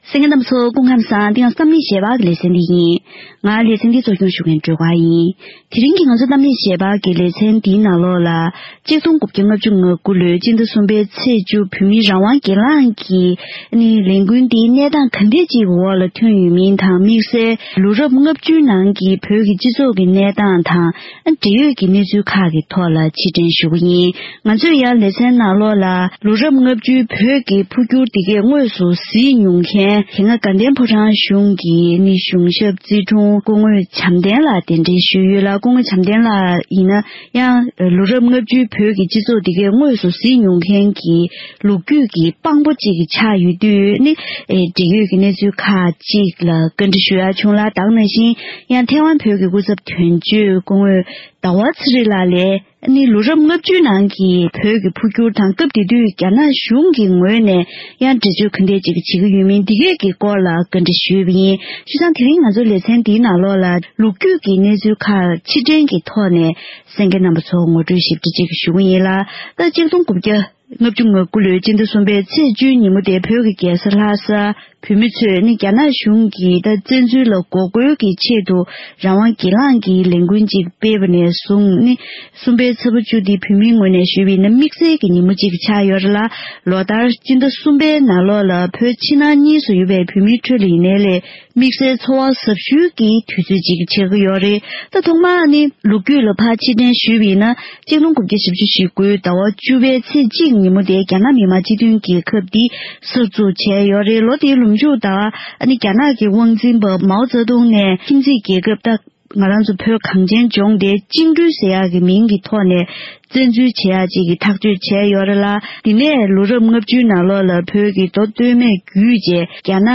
༄༅།།དེ་རིང་གི་གཏམ་གླེང་ཞལ་པར་ལེ་ཚན་ནང་ལོ་ངོ་༦༢གྱི་སྔོན་བོད་ཀྱི་རྒྱལ་ས་ལྷ་སར་ཐོན་པའི་བོད་མིའི་རང་དབང་སྒེར་ལངས་ཀྱིས་རྒྱ་ནག་དམར་པོའི་གཞུང་གི་བཙན་འཛུལ་ལ་ངོ་རྒོལ་བྱས་པའི་ལས་འགུལ་འདིའི་སྔ་རྗེས་ཀྱི་ཞིབ་ཕྲའི་གནས་ཚུལ་ཁག་གླེང་པ་ཞིག་གསན་རོགས་གནང་།།